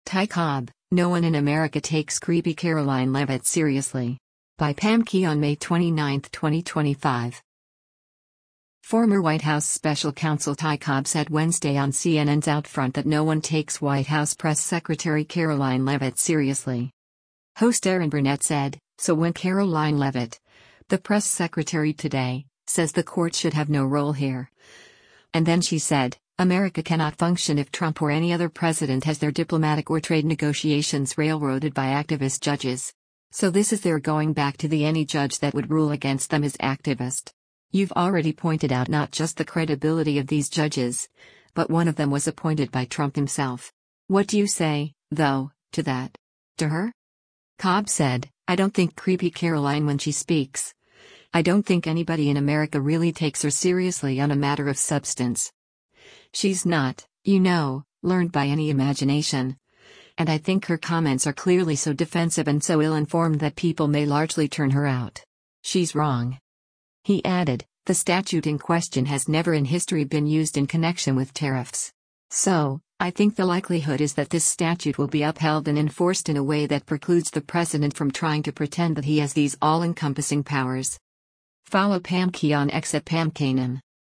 Former White House special counsel Ty Cobb said Wednesday on CNN’s “OutFront” that no one takes White House press secretary Karoline Leavitt seriously.